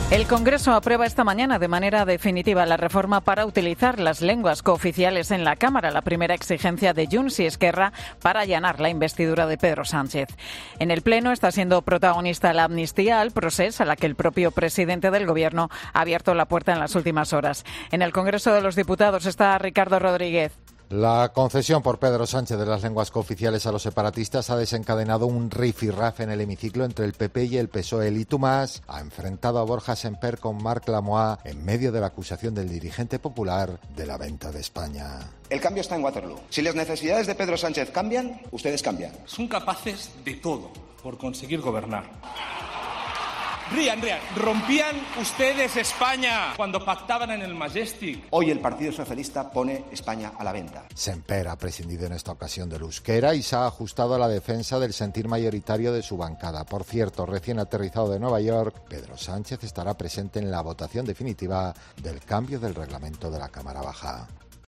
Durante el debate se ha producido un rifirrafe entre PP y PSOE a costa de la amnistía a Puigdemont y sus exigencias como esta reforma del Reglamento de la Cámara Baja
Borja Semper por el PP y Marc Lamuá por el PSOE se han enzarzado en un "y tú más"